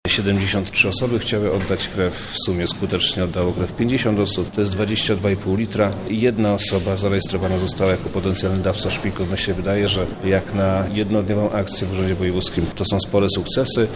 O wynikach mówi Przemysław Czarnek, wojewoda lubelski